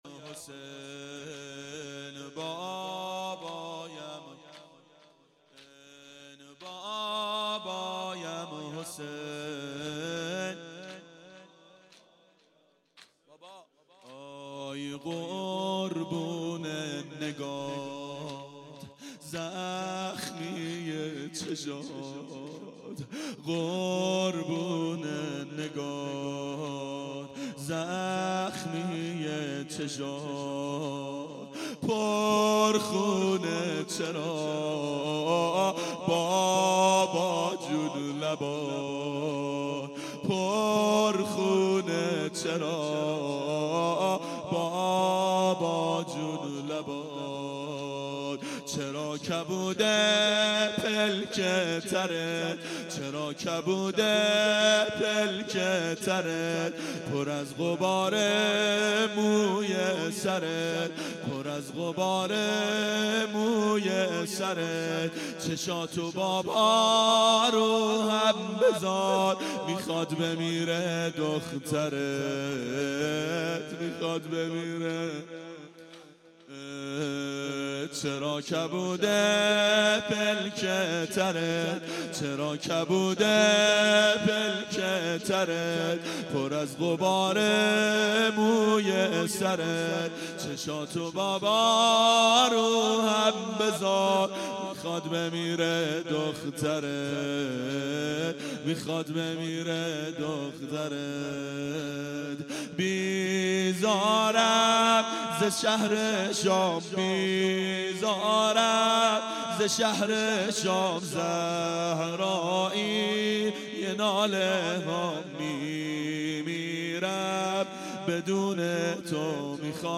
بابایم حسین - زمینه
• دهه اول صفر سال 1392 هیئت شیفتگان حضرت رقیه سلام الله علیها